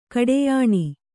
♪ kaḍeyāṇi